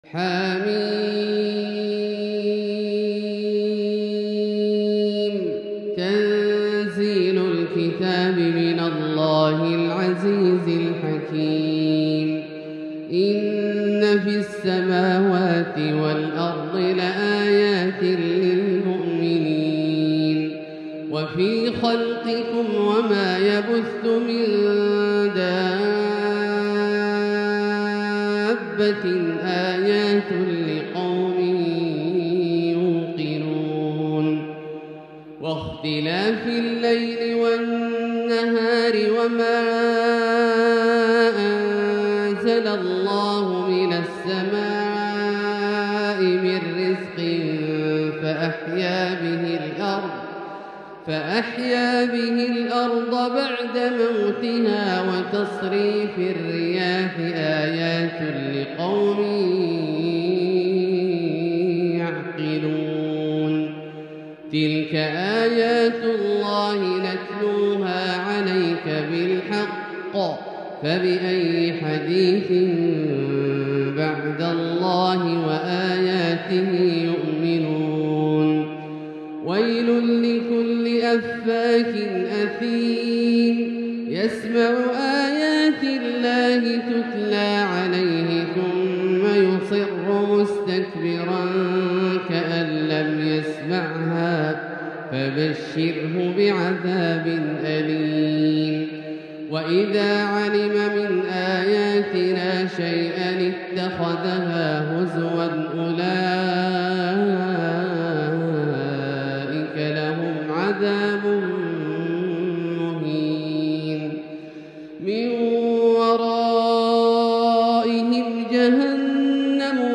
تلاوة جياشة رائقة لـ سورة الجاثية كاملة للشيخ د. عبدالله الجهني من المسجد الحرام | Surat Al-Jathiyah > تصوير مرئي للسور الكاملة من المسجد الحرام 🕋 > المزيد - تلاوات عبدالله الجهني